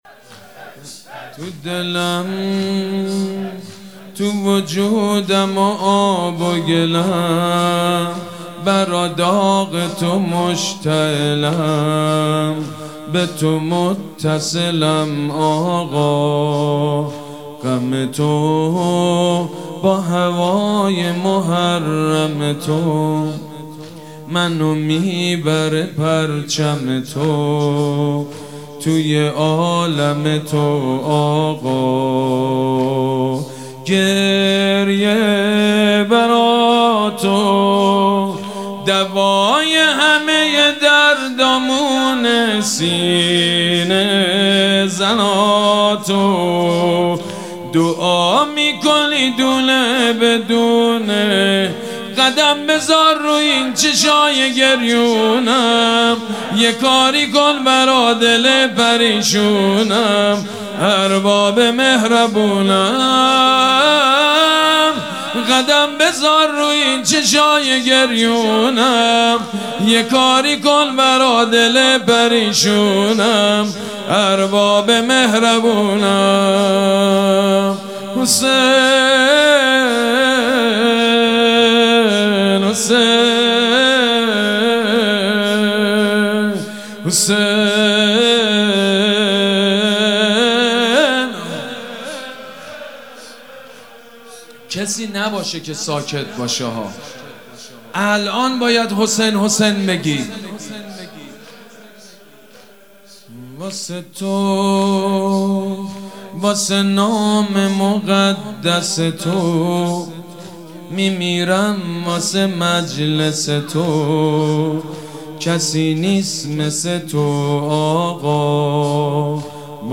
مداحی شب اول محرم 1399 با نوای حاج سید مجید بنی فاطمه